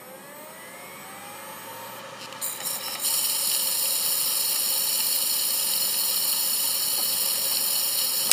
続いて、「シャーーーー」「カンカンカン」「ジーコジーコ」に代表される異音がする場合は、物理障害です。
「シャーーーー」のサンプル音
HDD-Errror-Sha-.mp3